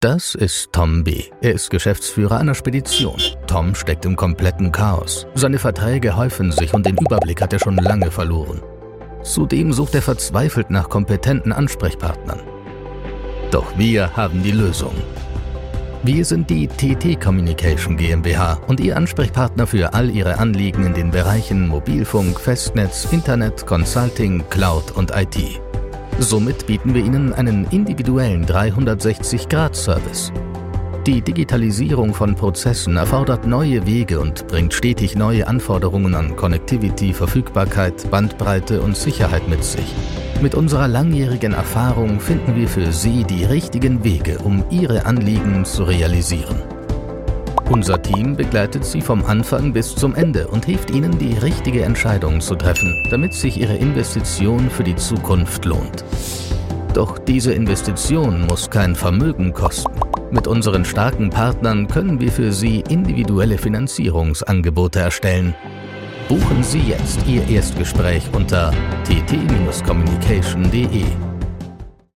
Natürlich, Vielseitig, Cool, Zuverlässig, Corporate
Erklärvideo